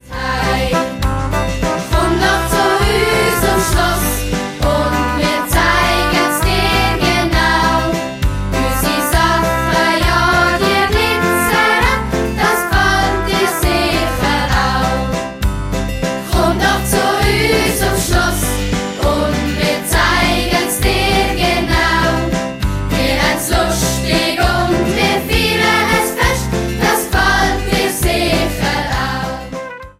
Schulmusical